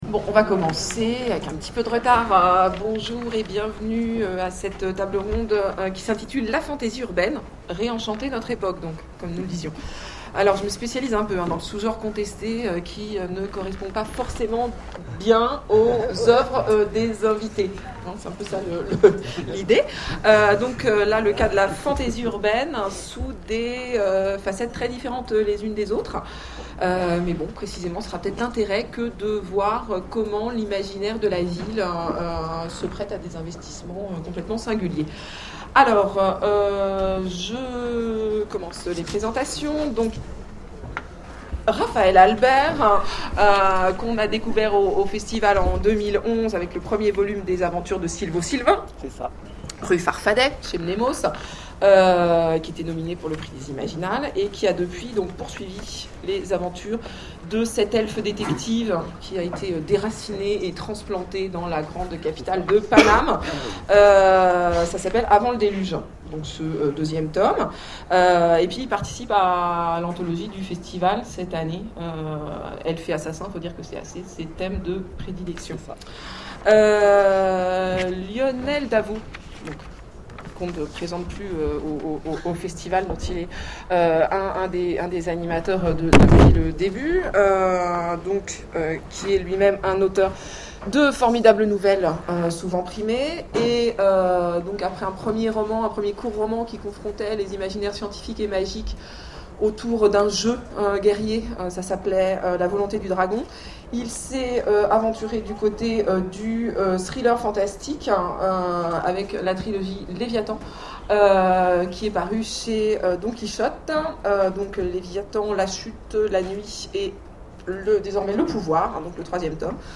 Imaginales 2013 : Conférence La fantasy urbaine